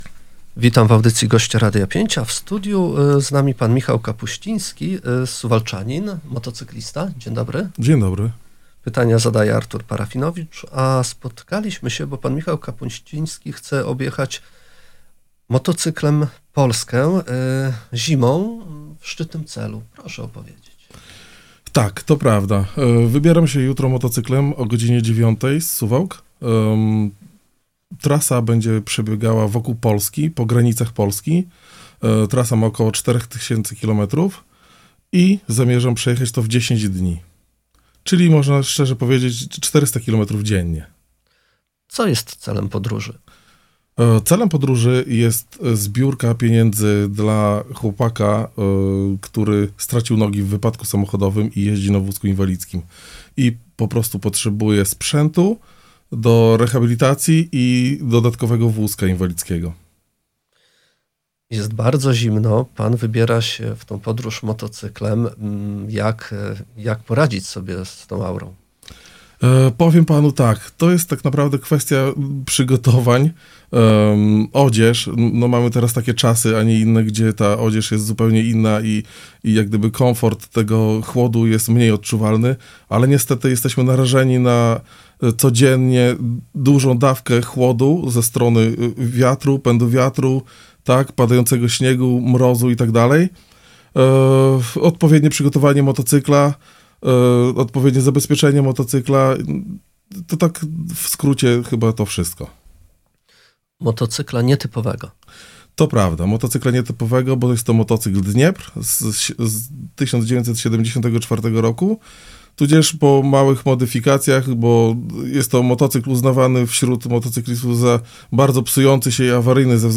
Cała rozmowa poniżej: